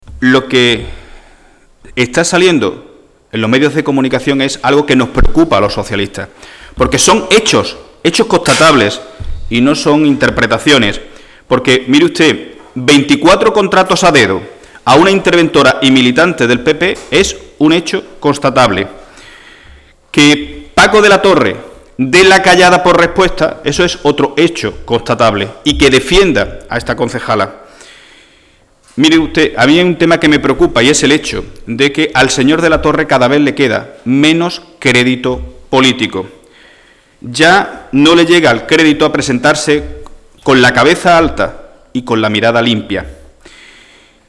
El secretario general del PSOE malagueño, Miguel Ángel Heredia, ha asegurado hoy en rueda de prensa que al alcalde de la ciudad, Francisco de la Torre, "cada vez le queda menos crédito político", en referencia a su actitud ante los escándalos que están salpicando a su concejal Teresa Porras.